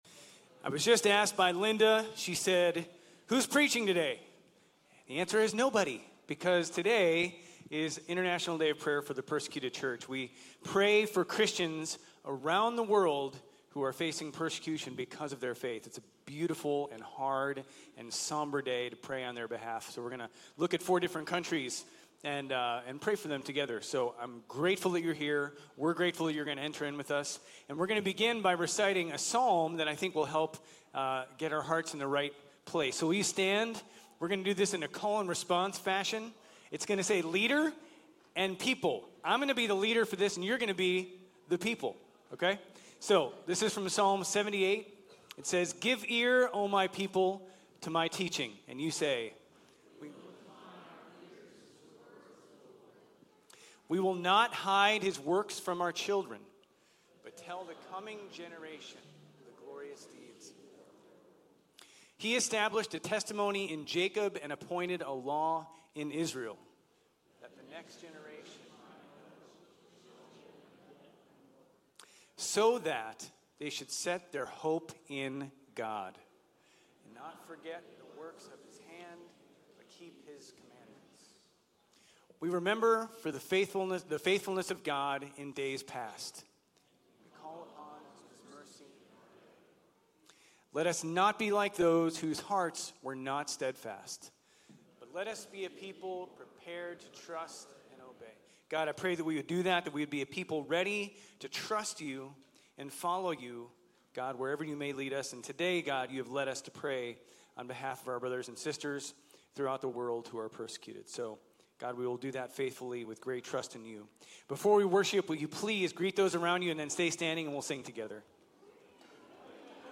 Grace Community Church Old Jacksonville Campus Sermons 11_9 Old Jacksonville Campus Nov 10 2025 | 01:09:36 1x 00:00 / 01:09:36 Subscribe Share RSS Feed Share Link Embed